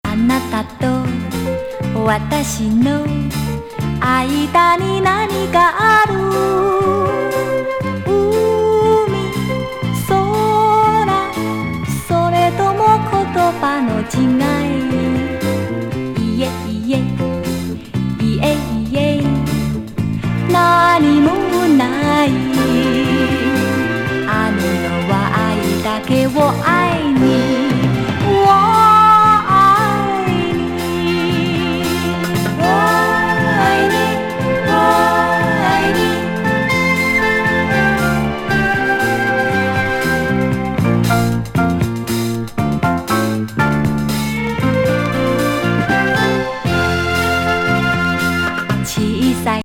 ズッタンズッタンにドラムが立ったアジアン・ファンキー日本語歌謡!